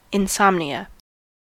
Ääntäminen
Synonyymit sleeplessness Ääntäminen US Haettu sana löytyi näillä lähdekielillä: englanti Määritelmät Substantiivit A sleeping disorder that is known for its symptoms of unrest and the inability to sleep .